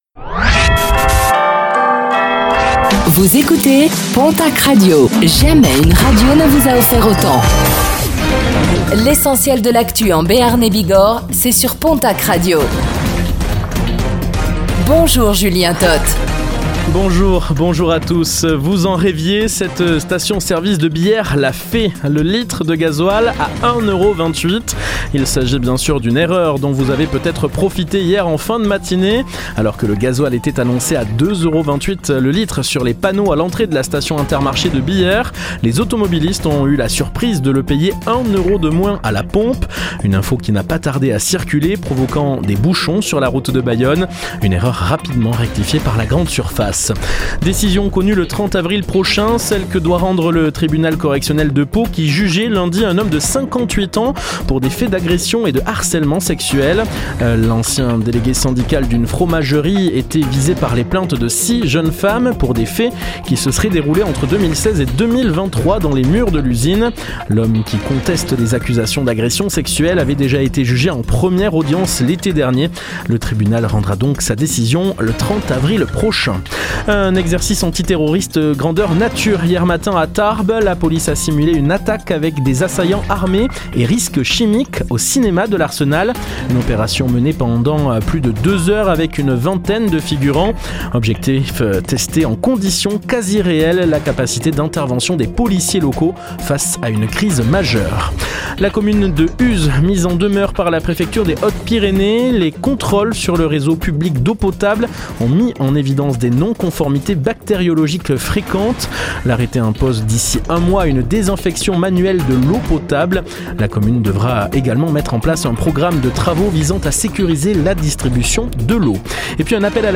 Infos | Mercredi 1er avril 2026